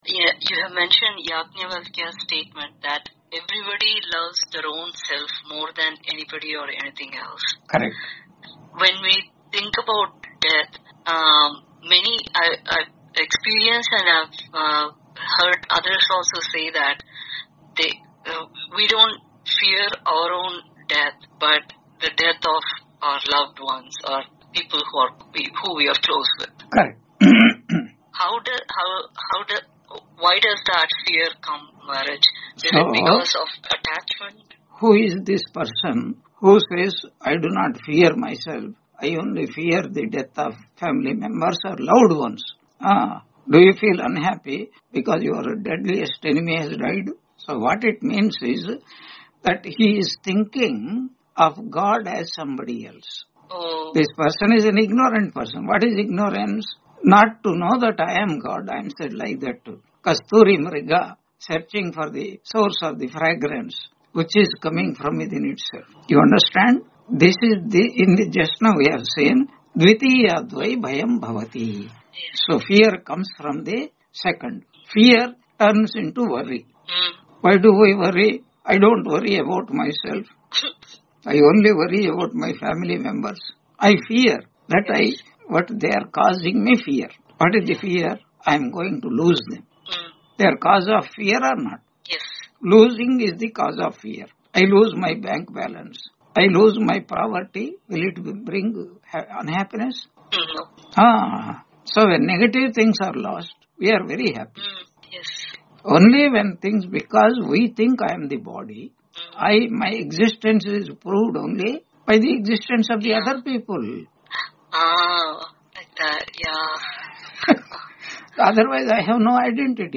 Taittiriya Upanishad Lecture 86 Ch2 7.1-2 on 07 January 2026 Q&A - Wiki Vedanta